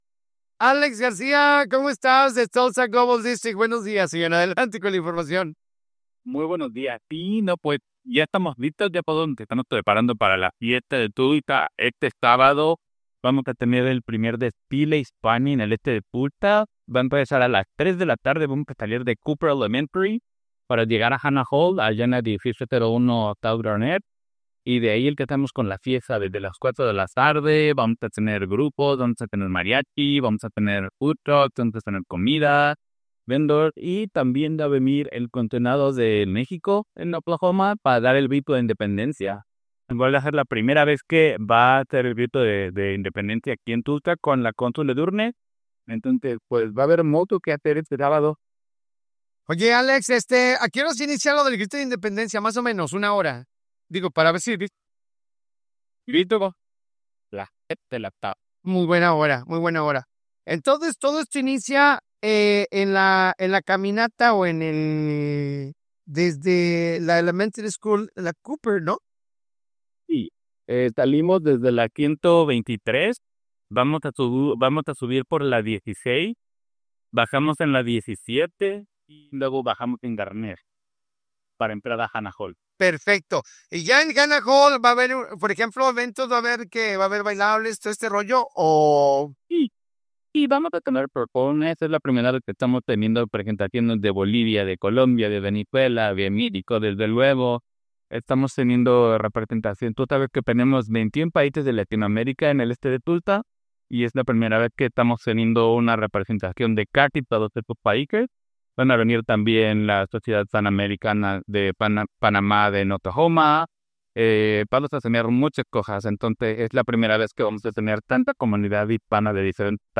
Entrevista-TulsaGlobaldisctrict-10Septiembre25-.mp3